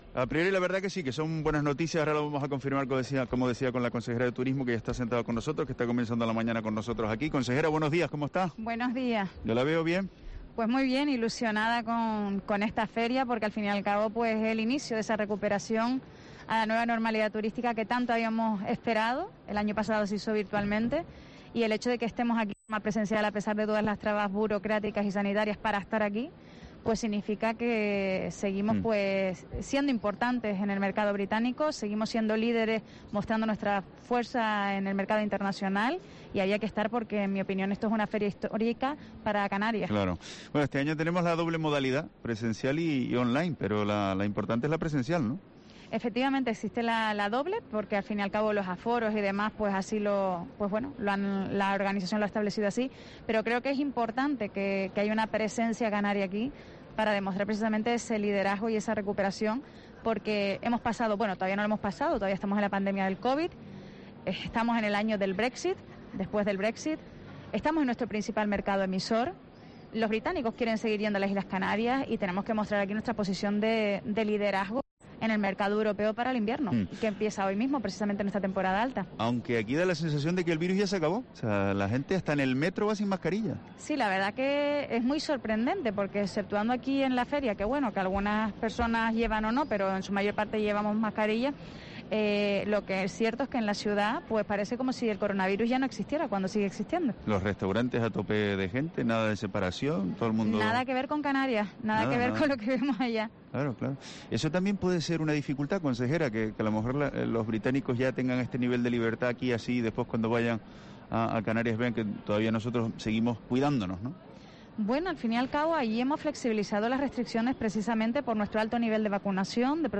Entrevista a Yaiza Castilla, consejera de Turismo del Gobierno de Canarias, en la World Travel Market